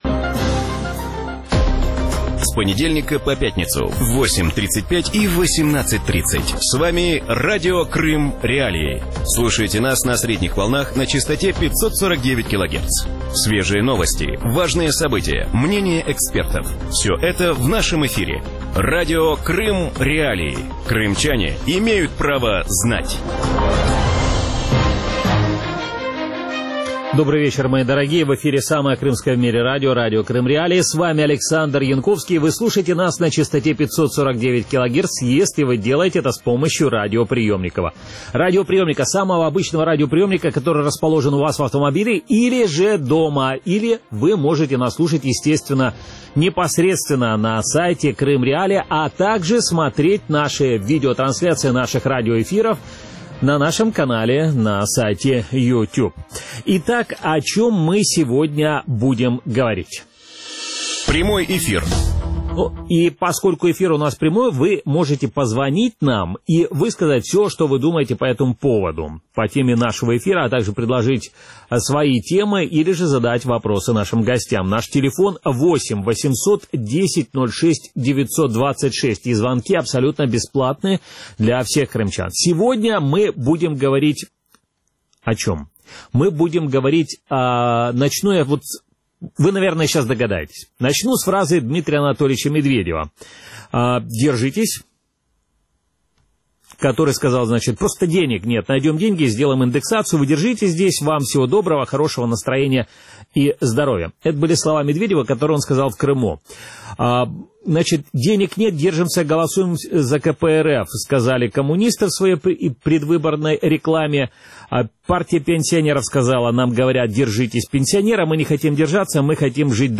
В вечернем эфире Радио Крым.Реалии обсуждают ситуацию с пенсионным обеспечением в России и в Крыму. Стоит ли крымчанам ожидать индексации пенсий, зачем государство решило выплатить пенсионерам по 5000 рублей и какие бюджетные расходы российское правительство урежет в следующем году?